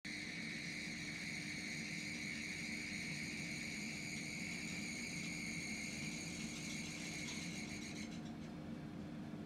Bruit de ferraille et sifflements assez forts chaudière gaz Saunier Duval | Questions / Réponses Chauffage
Et elle fait un boucan pas possible a chaque fois que le brûleur s’allume.
Il s’allume, quelques secondes après j’ai un bruit de ferraille puis des sifflements assez forts qui durent quelques secondes.
Bruit chaudière Saunier Duval
pb-bruit-chaudiere-saunier-duval.mp3